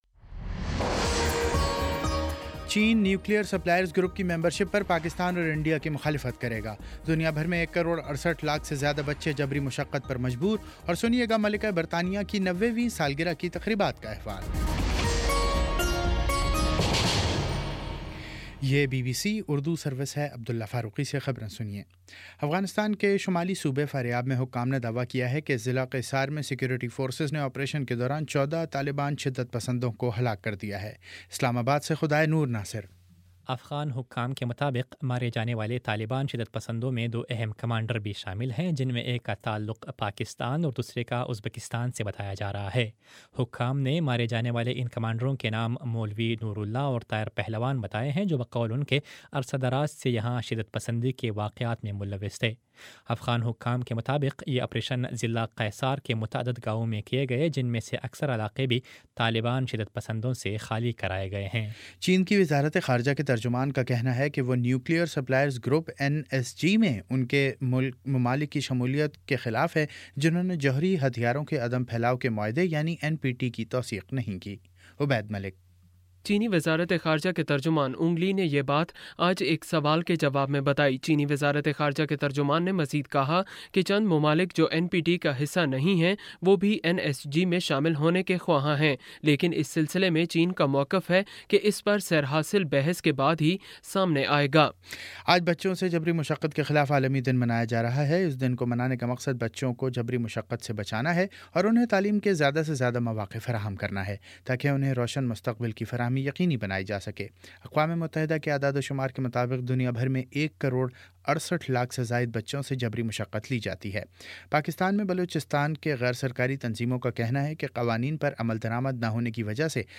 جون 12 : شام چھ بجے کا نیوز بُلیٹن